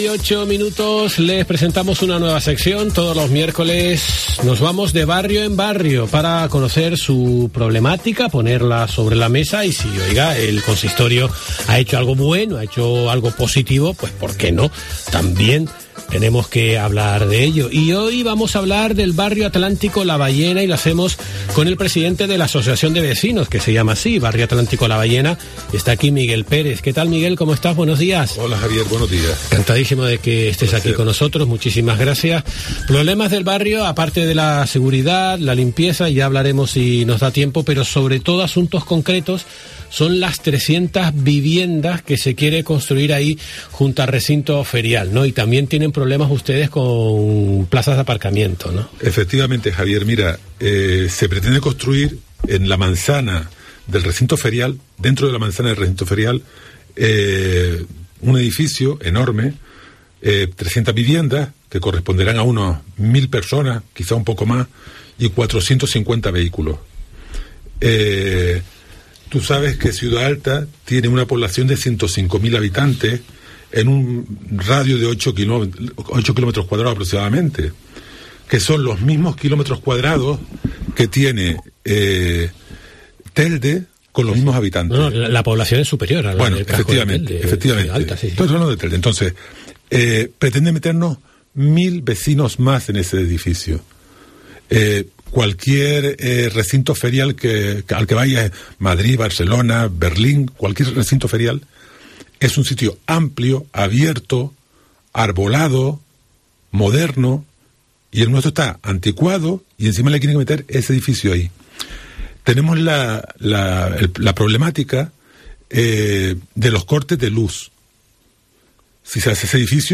De barrio en barrio: Entrevista